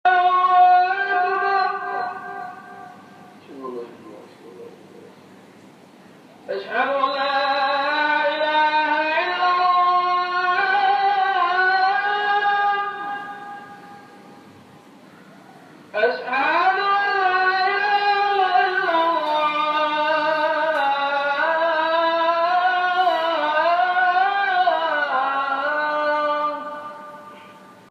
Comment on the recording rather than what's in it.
As I was waiting for my food to come, the speakers on the mosque blasted the afternoon call to prayer (ya, it was LOUD):